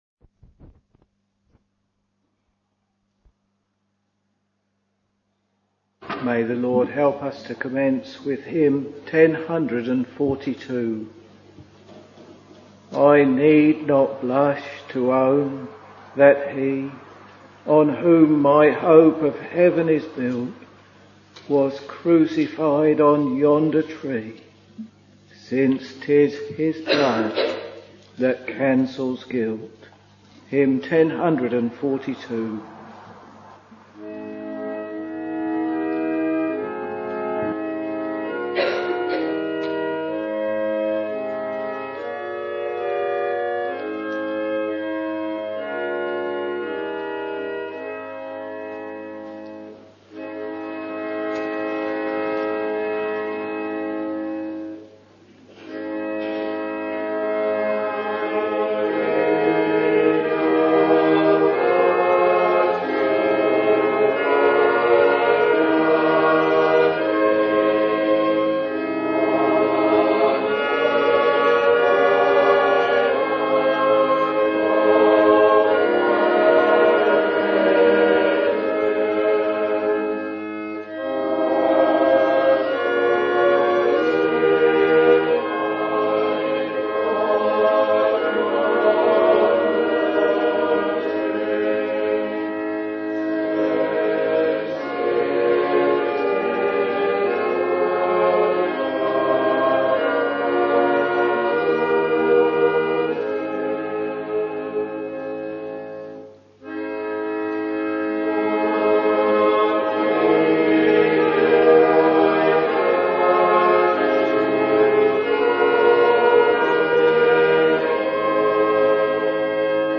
Evening Service Preacher